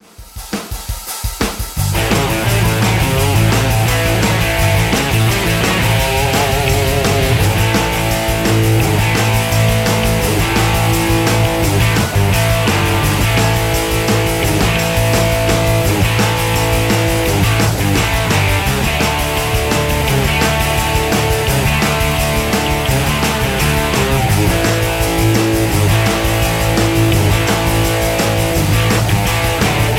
MPEG 1 Layer 3 (Stereo)
Backing track Karaoke
Rock, Oldies, 1960s